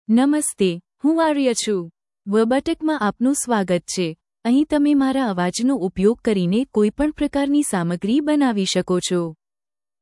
Aria — Female Gujarati (India) AI Voice | TTS, Voice Cloning & Video | Verbatik AI
Aria is a female AI voice for Gujarati (India).
Voice sample
Listen to Aria's female Gujarati voice.
Female
Aria delivers clear pronunciation with authentic India Gujarati intonation, making your content sound professionally produced.